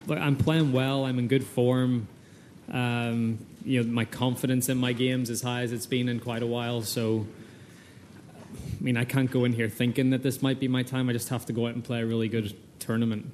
Mcllroy speaks on his confidence entering the Championship.